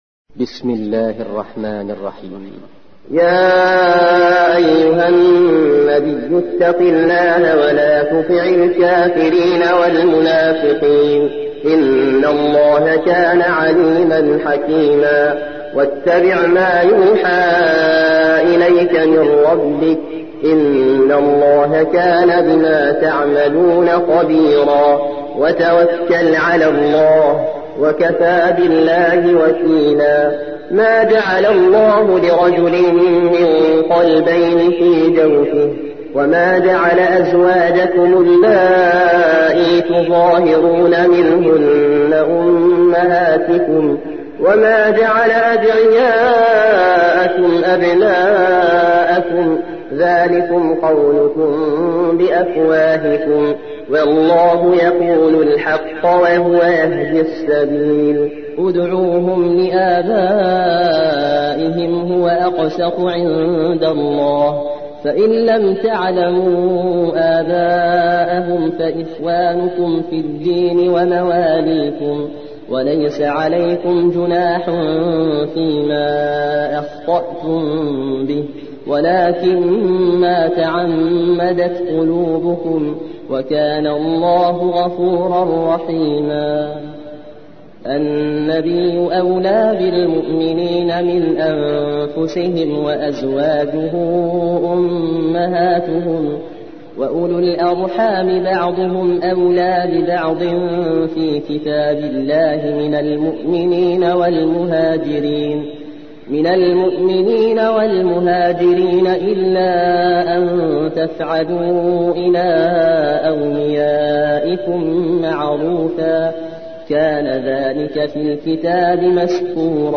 33. سورة الأحزاب / القارئ